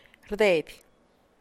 rdeidy[rdèèi’dy]